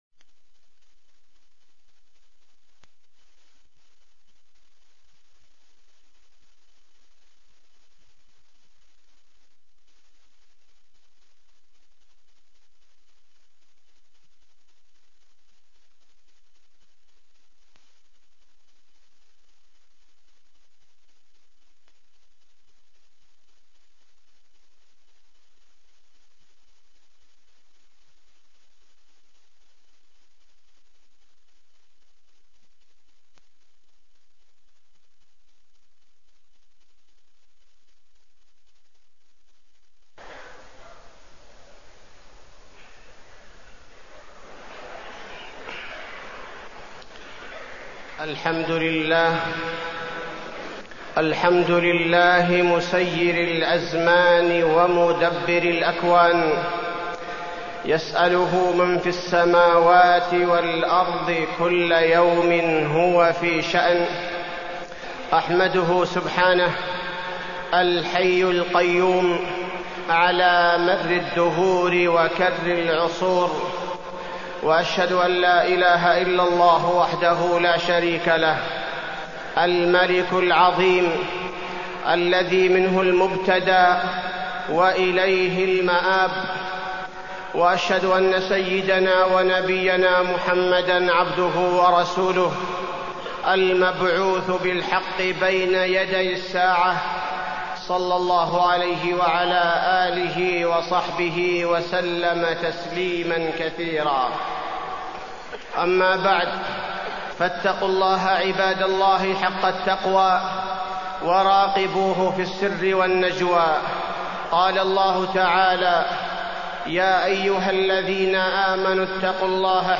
تاريخ النشر ٢٩ ذو الحجة ١٤٢٤ هـ المكان: المسجد النبوي الشيخ: فضيلة الشيخ عبدالباري الثبيتي فضيلة الشيخ عبدالباري الثبيتي محاسبة النفس مع العام المنصرم